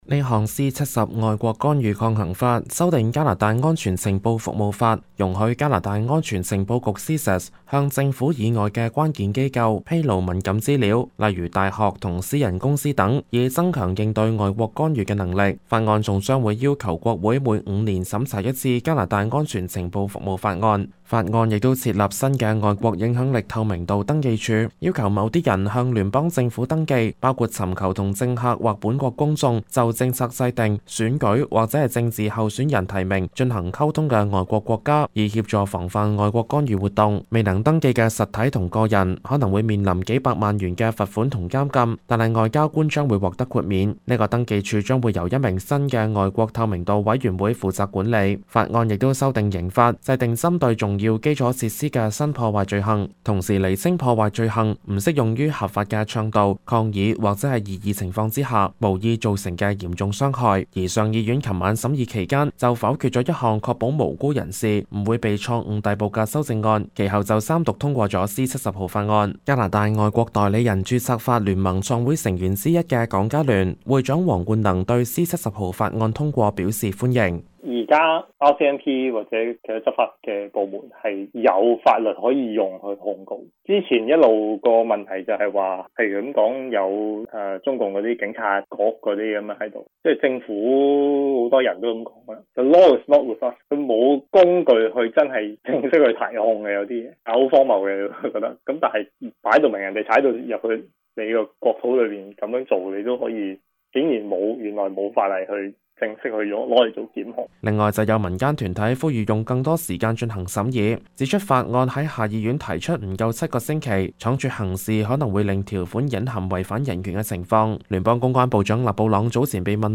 news_clip_19391.mp3